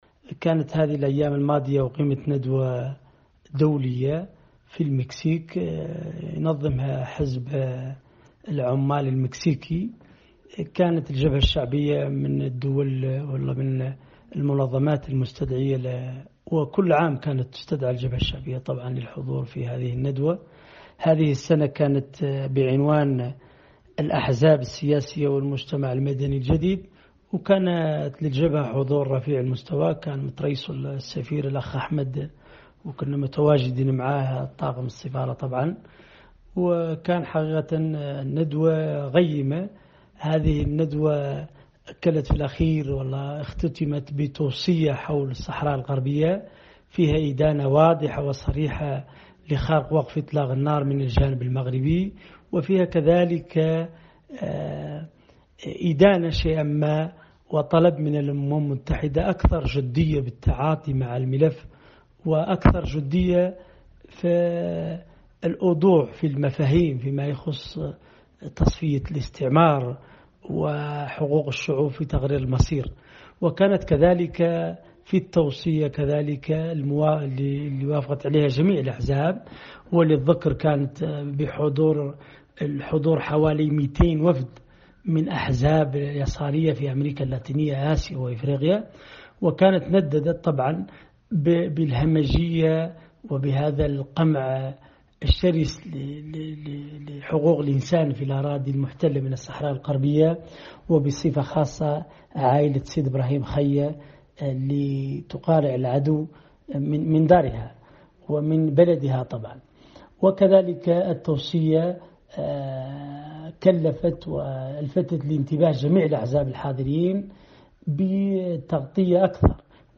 المختار لبيهي – قيادي في جبهة البوليساريو وسفير الصحراء الغربية في المكسيك
وفي تصريح خاص لـ”أفريقيا برس” أشار لبيهي إلى أن التوصيات التي خرج بها الملتقى اشترك في طرحها أكثر من مئتي حزب يساري من دول أمريكا اللاتينية وآسيا وأفريقيا، إلى جانبها حضور رفيع المستوى للجبهة الشعبية، واصفاً أعمال الملتقى بالقيّمة.